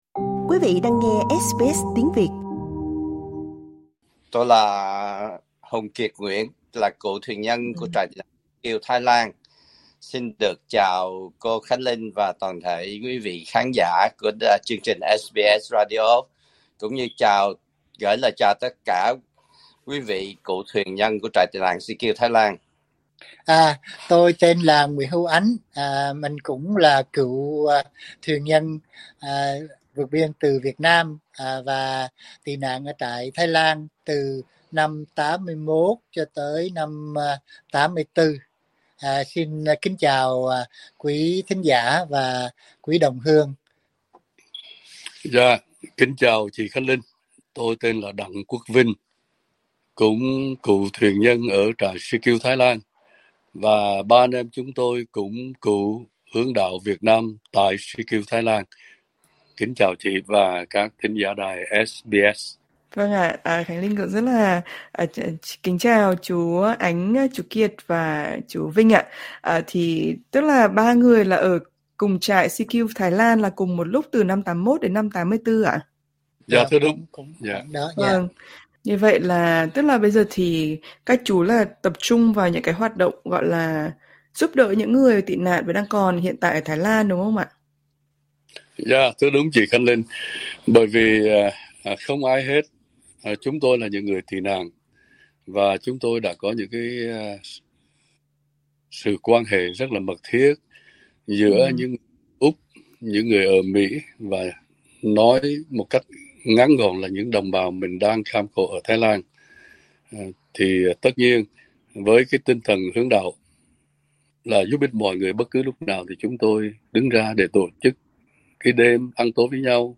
Trò chuyện với SBS Tiếng Việt